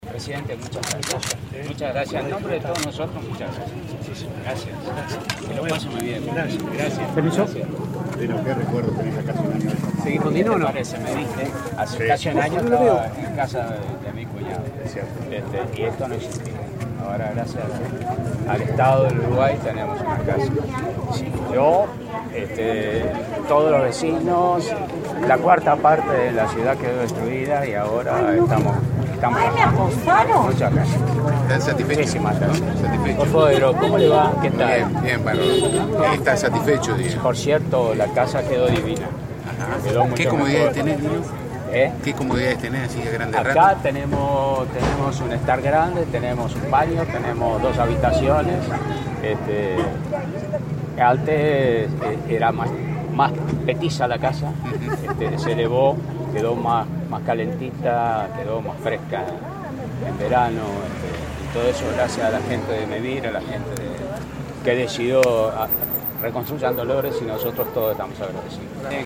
El músico Gastón Ciarlo, "Dino", agradeció al presidente Vázquez y al Estado por la nueva casa recibida en el marco de la reconstrucción de Dolores. “La casa quedó divina”, sostuvo en diálogo con la prensa.